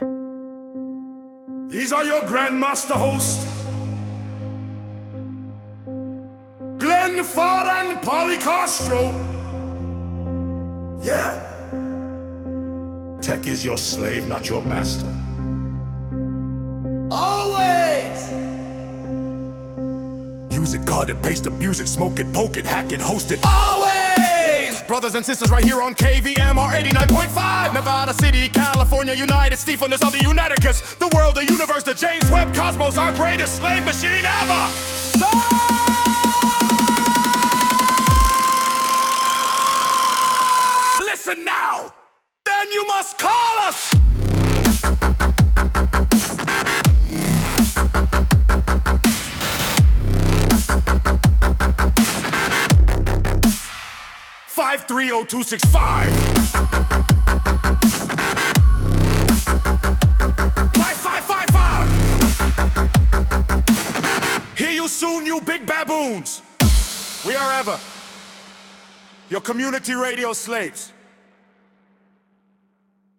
Hoooo AI Music Sen Tech Show…